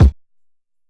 Havoc Kick 26.wav